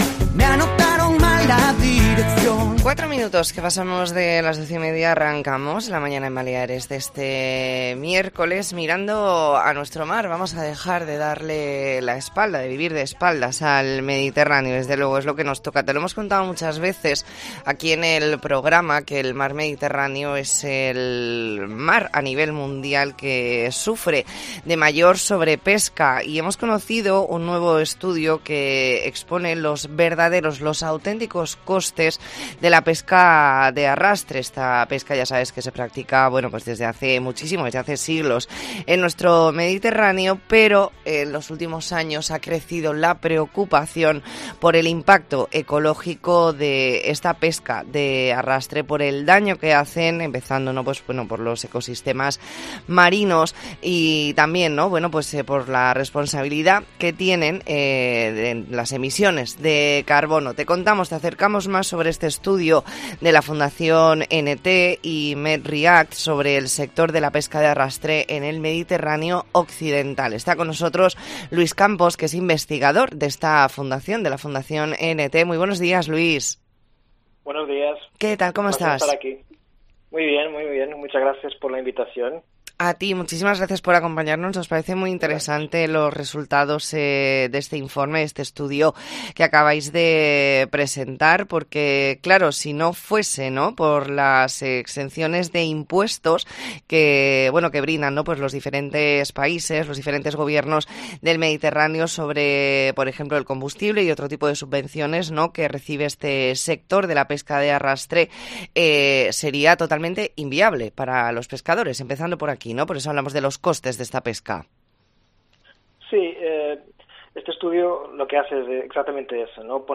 E ntrevista en La Mañana en COPE Más Mallorca, miércoles 6 de julio de 2022.